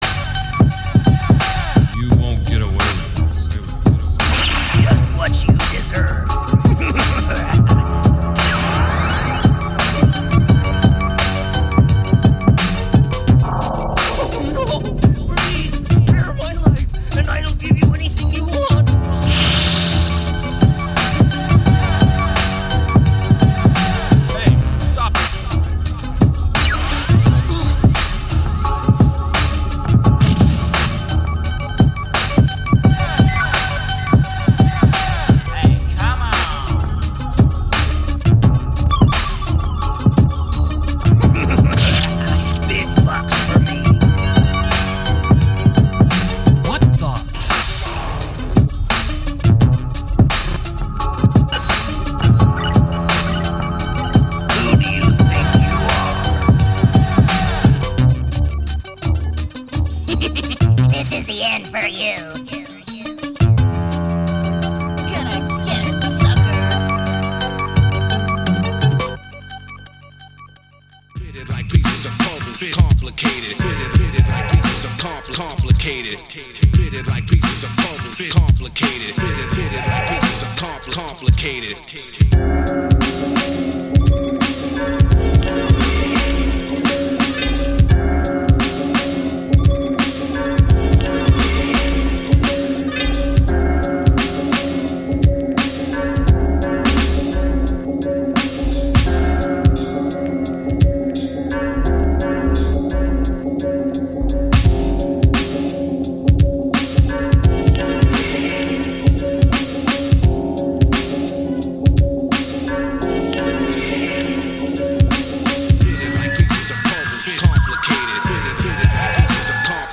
RobotDJ-sets and live interventions!